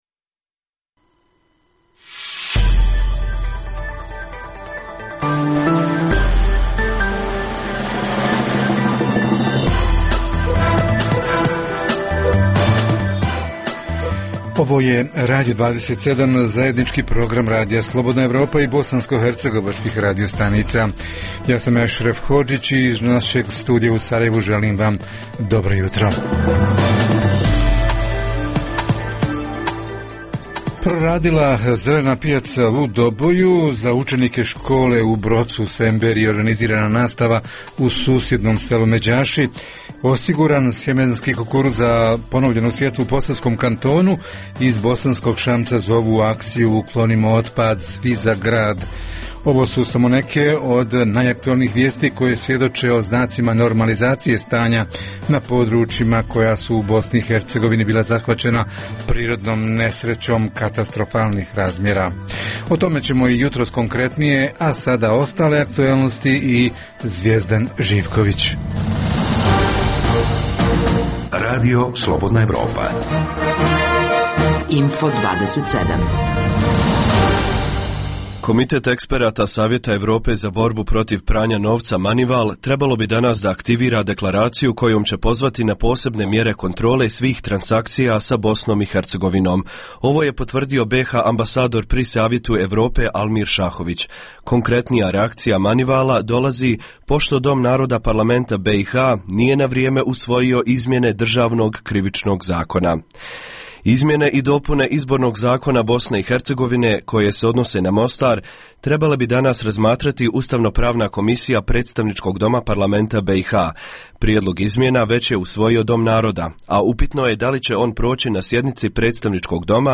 U općini Tuzla još uvijek aktivno oko dvije hiljade klizišta: za pet porodica čije su kuće uništene, sinoć uručena donacija od po 2.400 maraka. Info-plus jutros izravno i u Mostaru: iako je na snazi „žuti alarm“, meteorolozi upozoravaju na visoke temperature.